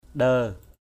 /ɗə:/